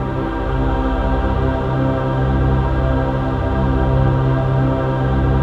DM PAD2-86.wav